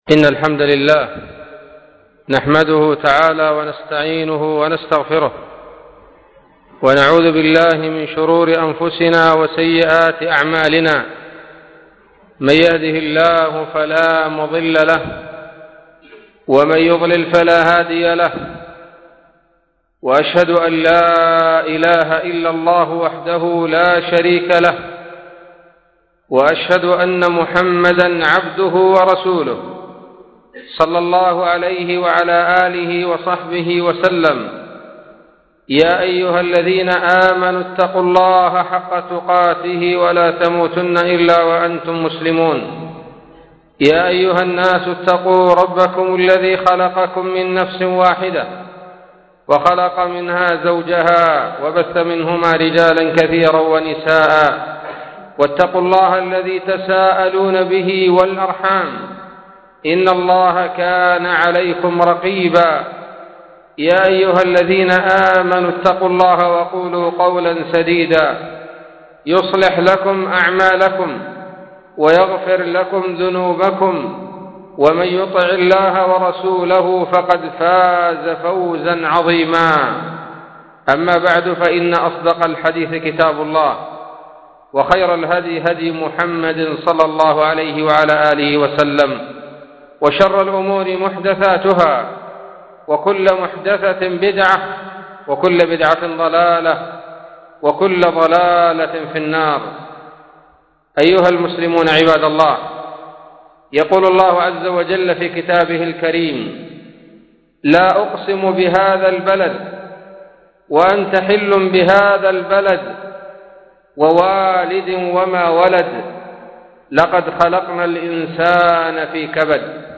خطبة بعنوان : ((علاج الهموم [1]))